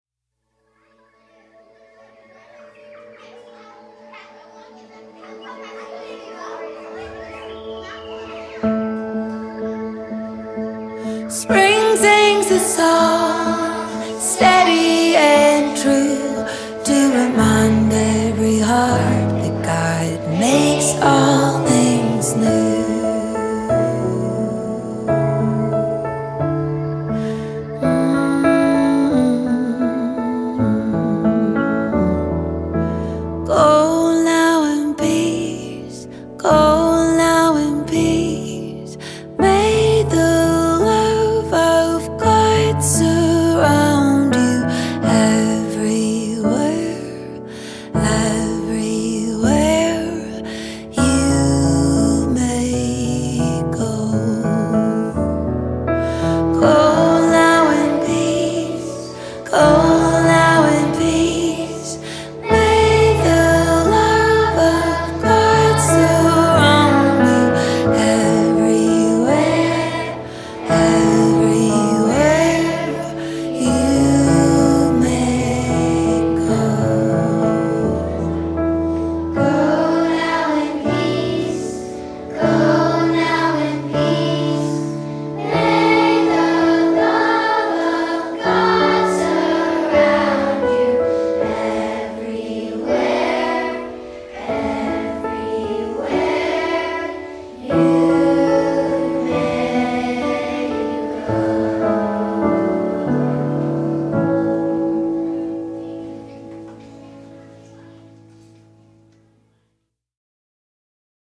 68 просмотров 71 прослушиваний 3 скачивания BPM: 125